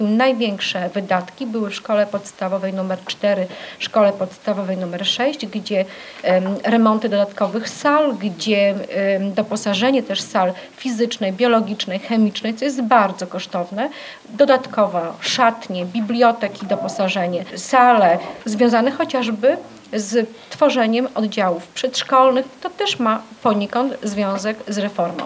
Szczegóły sprawy na ostatniej sesji Rady Miejskiej przedstawiła Ewa Sidorek, zastępca prezydenta miasta między innymi do spraw oświaty.